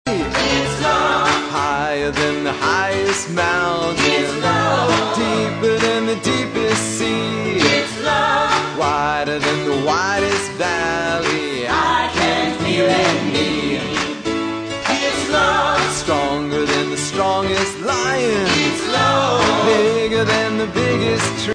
Celebrate love and celebrate with gospel music.